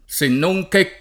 se non che [Se nnoj k%+] o sennonché [id.] (meno bene senonché [id. o Senojk%+]) cong. («ma…») — sempre in gf. divisa e con differente accentaz. [